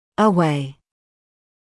[ə’weɪ][э’уэй]далеко, вдали; вдаль; прочь (от), в сторону (away from)